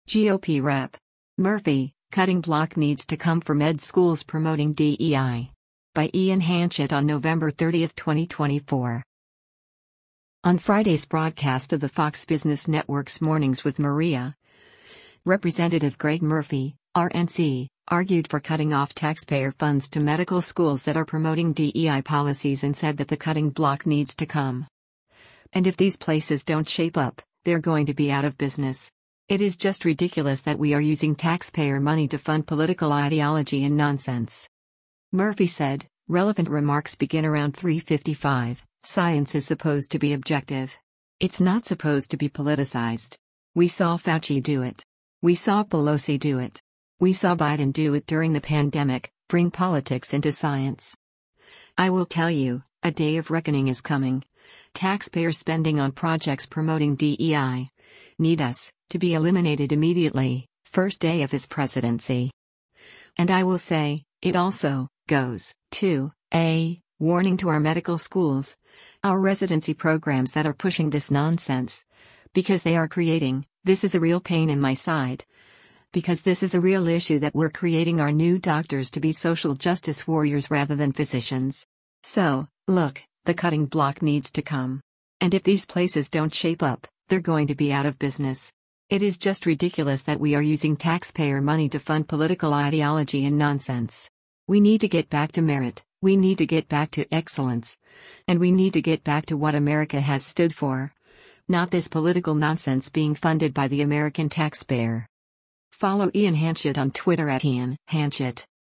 On Friday’s broadcast of the Fox Business Network’s “Mornings with Maria,” Rep. Greg Murphy (R-NC) argued for cutting off taxpayer funds to medical schools that are promoting DEI policies and said that “the cutting block needs to come. And if these places don’t shape up, they’re going to be out of business. It is just ridiculous that we are using taxpayer money to fund political ideology and nonsense.”